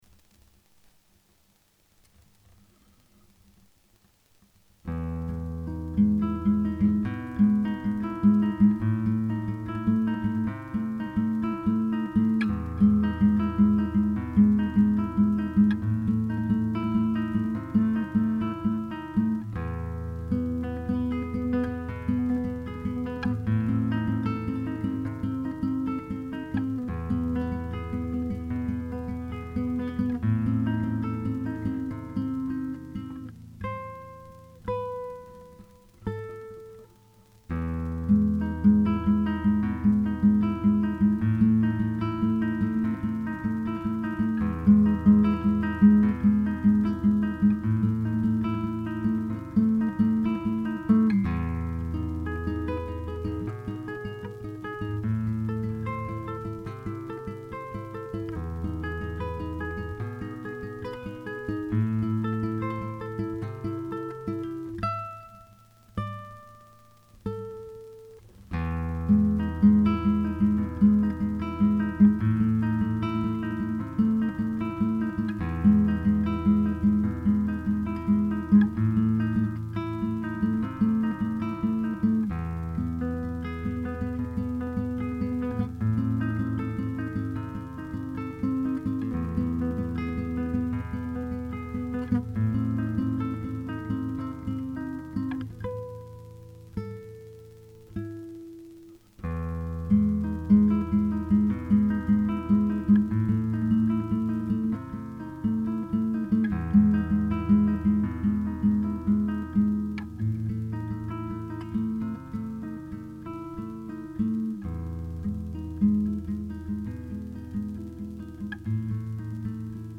Musique